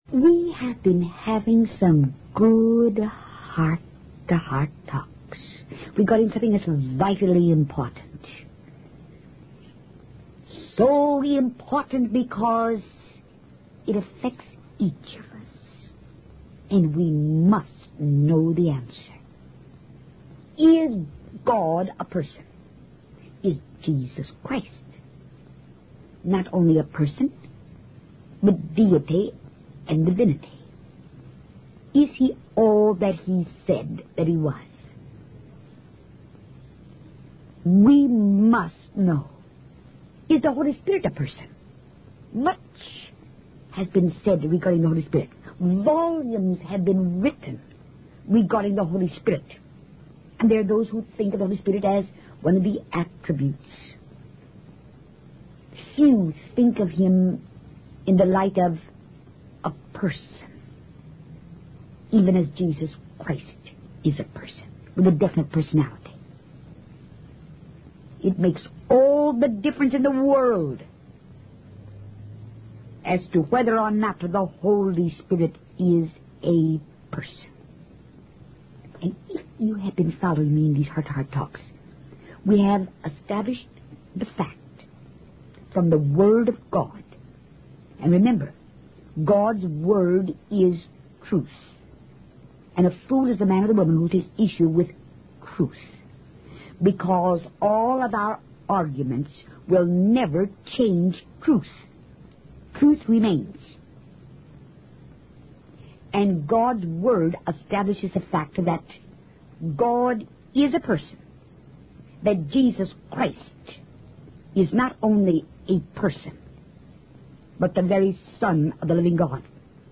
In this sermon, the speaker reflects on the comforting presence of God in times of darkness and despair.